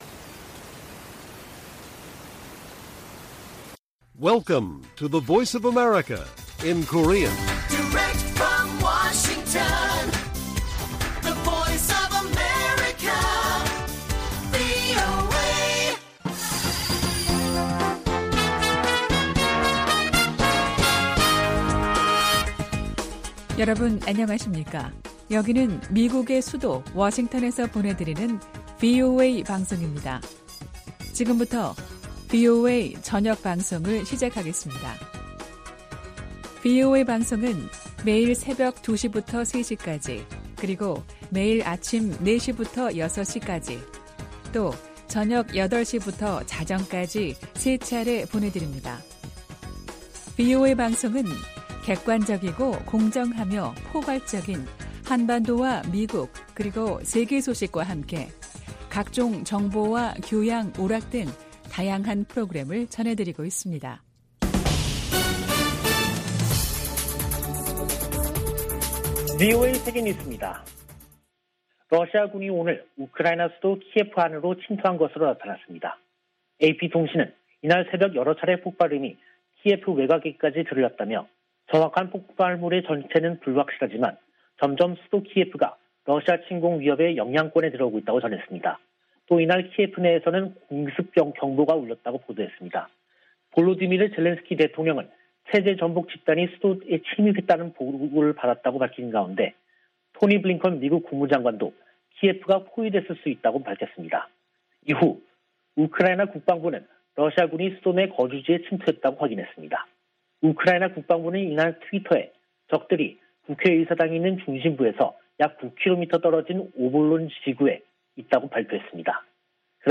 VOA 한국어 간판 뉴스 프로그램 '뉴스 투데이', 2022년 2월 25일 1부 방송입니다. 미 공화당 의원들은 러시아의 우크라이나 무력 침공에 대해 북한 등에 미칠 악영향을 우려하며 강력한 대응을 촉구했습니다. 우크라이나 침공으로 조 바이든 행정부에서 북한 문제가 뒤로 더 밀리게 됐다고 미국 전문가들은 진단했습니다. 유엔은 인도주의 기구들의 대북 송금이 막힌 문제를 해결하기 위해 특별 해법을 논의하고 있다고 밝혔습니다.